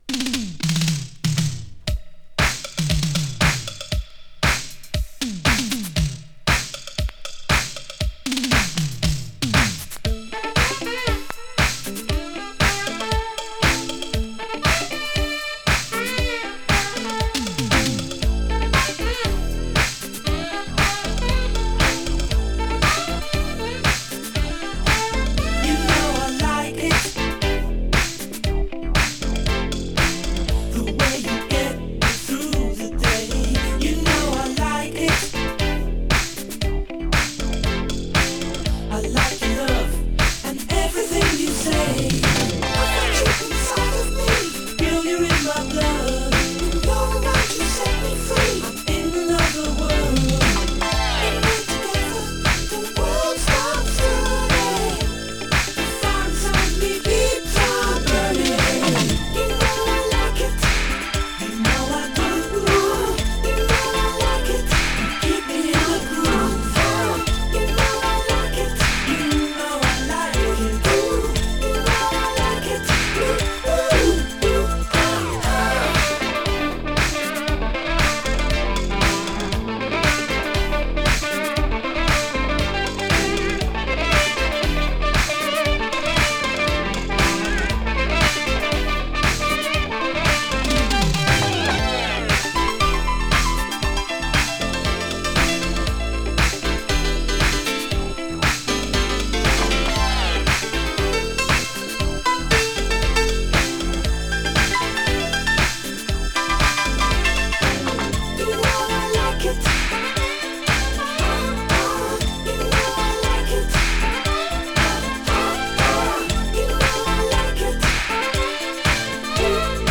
Brit Funk!
electric boogie!
[UK] [BOOGIE]